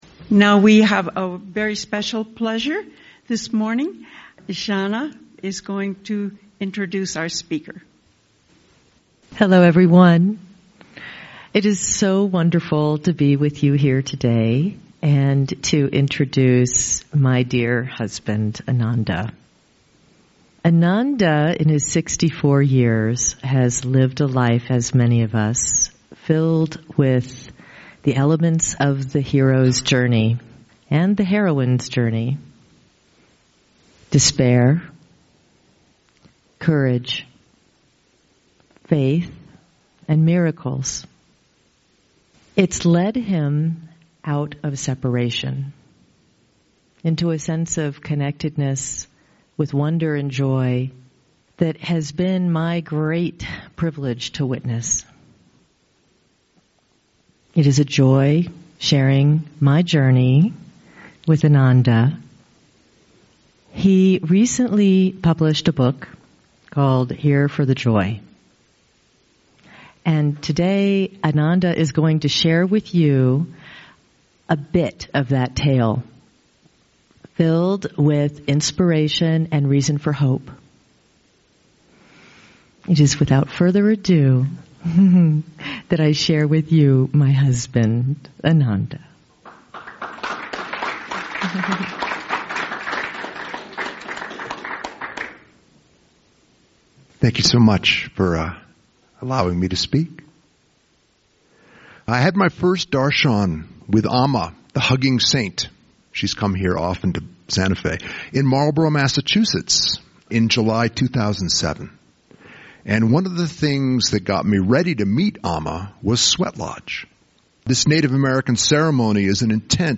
Talk given at the Celebration in Santa Fe, NM in May, 2023 about meeting Amma and the miraculous healing I received from Her.